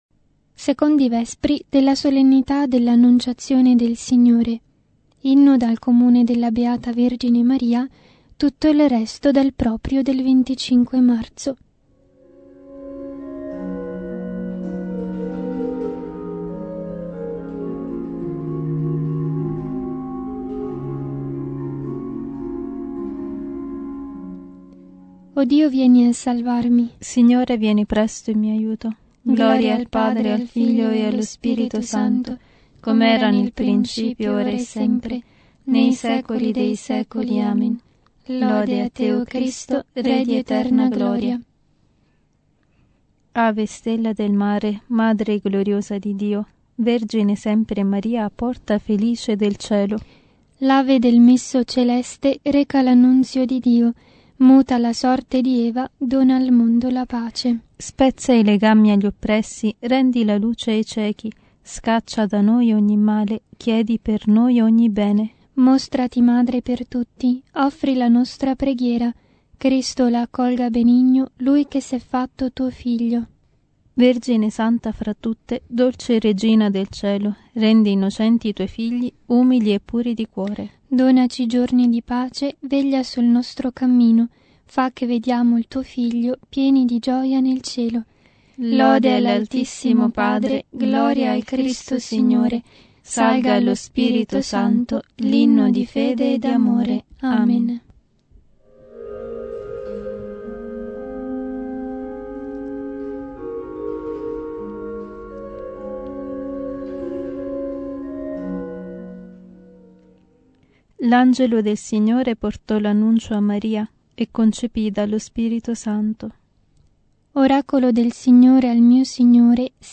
Secondi vespri – Solennità dell’Annunciazione